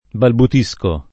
balbutire
balbutisco [ balbut &S ko ], ‑sci